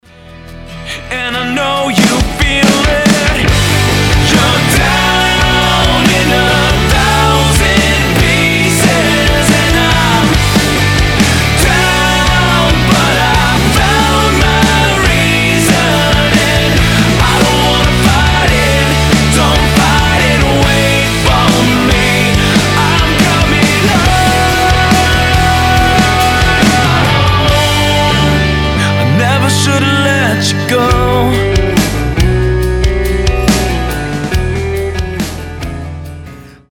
красивый мужской голос
Alternative Rock
Modern Rock